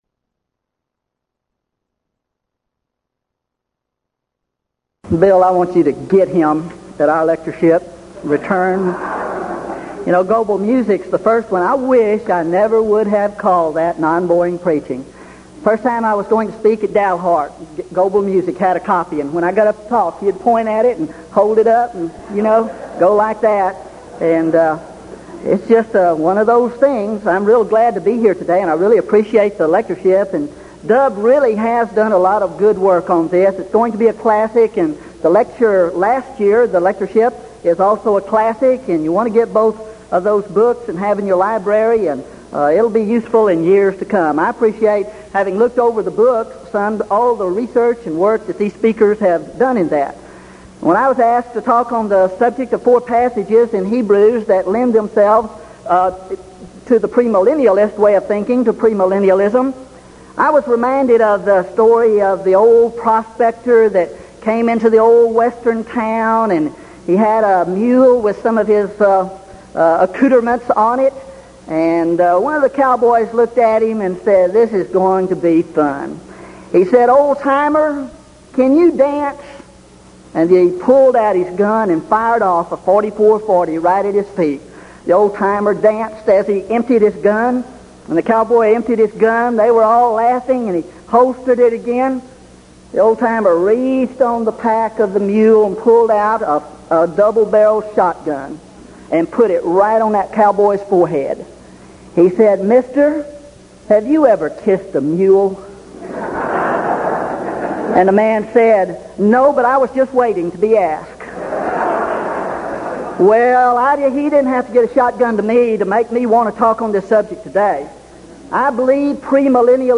Series: Denton Lectures Event: 1983 Denton Lectures